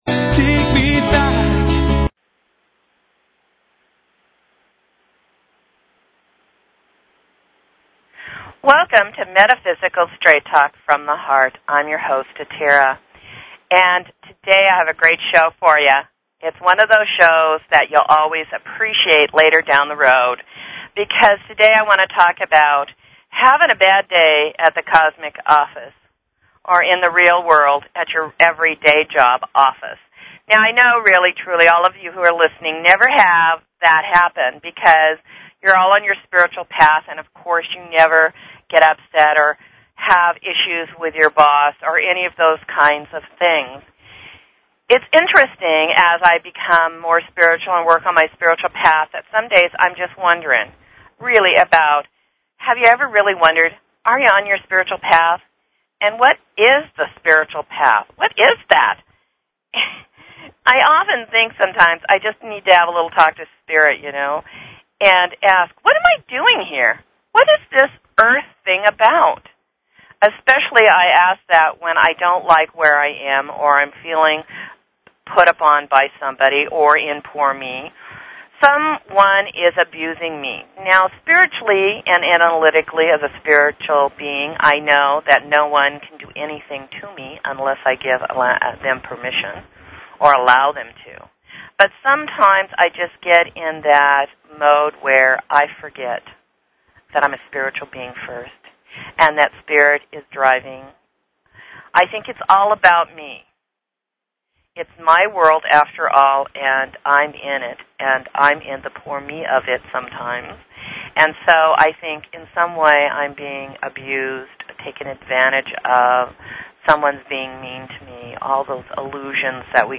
Talk Show Episode, Audio Podcast, Metaphysical_Straight_Talk and Courtesy of BBS Radio on , show guests , about , categorized as